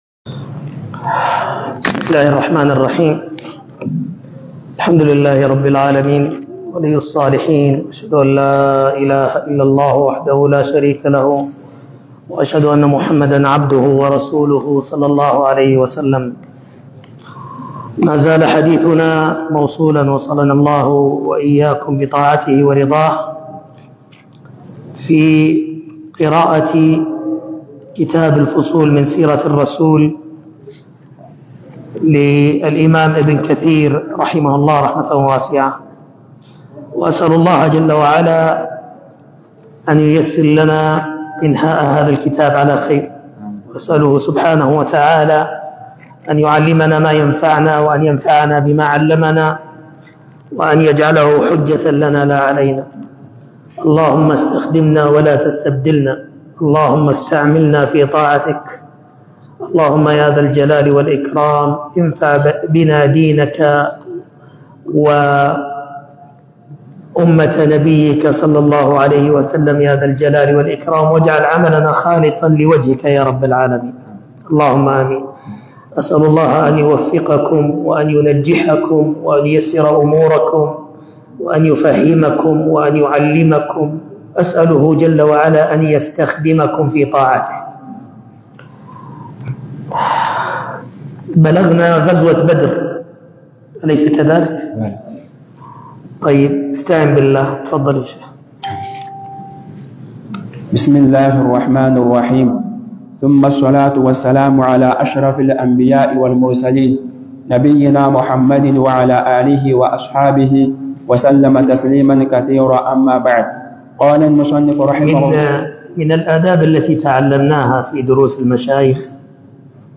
الفصول في سيرة الرسول الدرس السابع ( غزوة بدر الكبرى